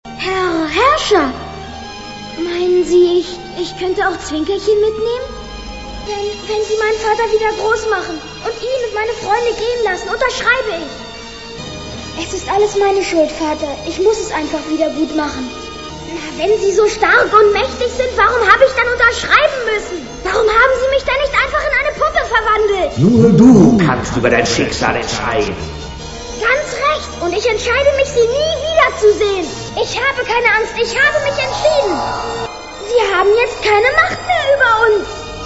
Berliner Synchro mit Top-Berliner Besetzung und eingedeutschten Songs.